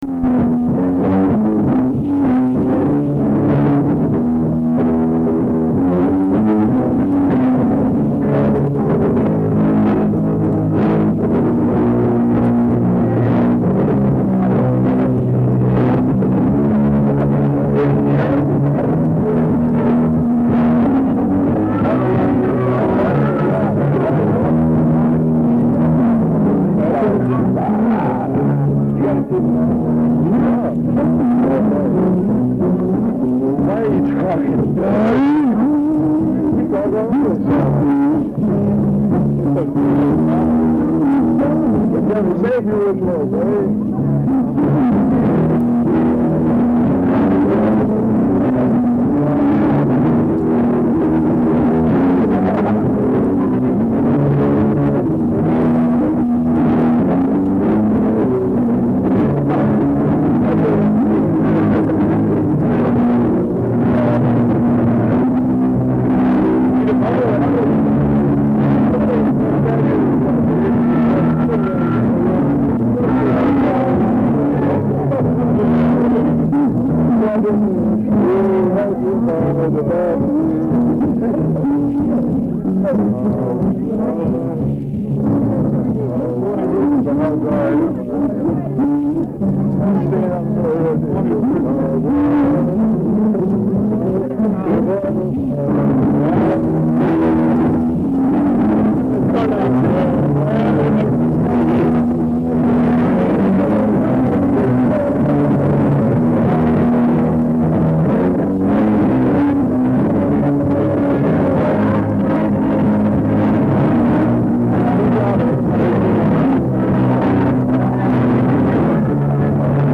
This was an outdoor concert held on a Sunday afternoon in the summer of 1972 in Harrison Smith Park in Upper Sandusky, Ohio.
Disclaimer: The audio in the clips is of extremely poor quality! Plus my friends and I were talking throughout.